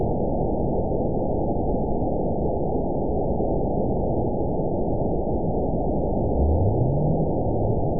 event 920365 date 03/19/24 time 00:11:47 GMT (1 year, 3 months ago) score 9.36 location TSS-AB07 detected by nrw target species NRW annotations +NRW Spectrogram: Frequency (kHz) vs. Time (s) audio not available .wav